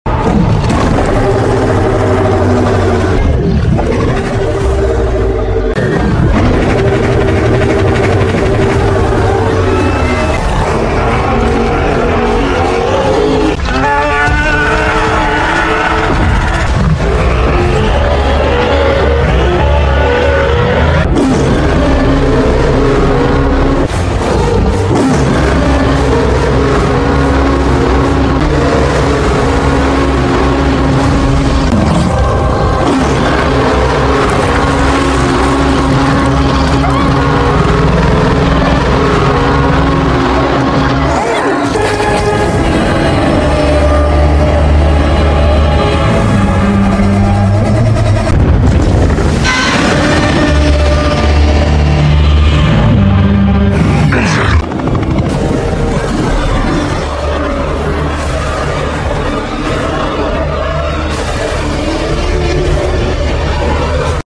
Whick roar is your favorite? sound effects free download